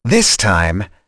Fluss-vox-trs-02.wav